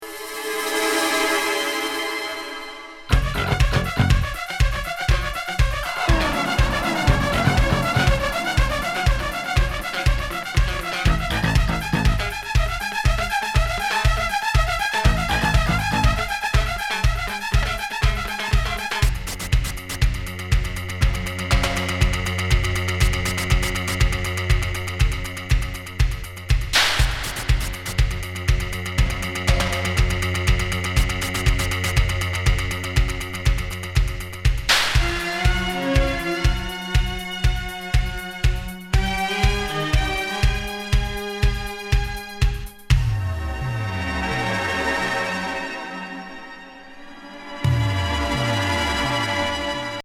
ビザール・ディスコ化！